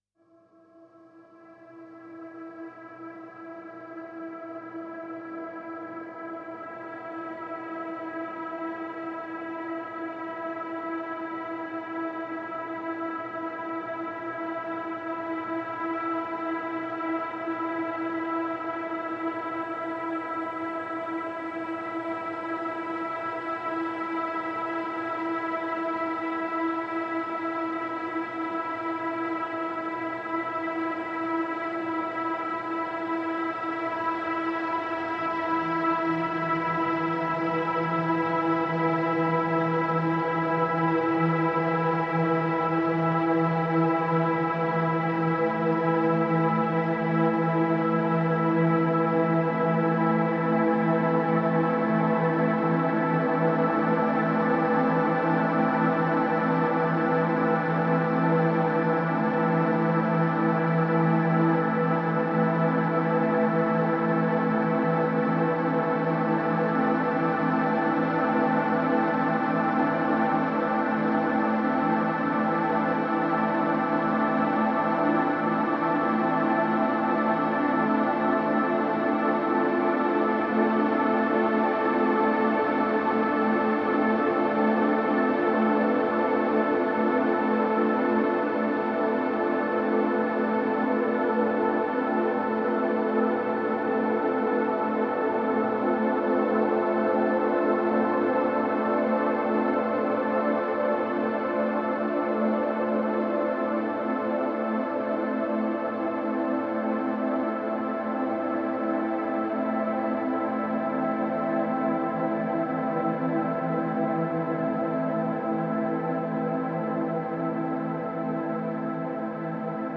Serious pads fill the air with a heavy mournful sentiments.